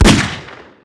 revolverShoot.ogg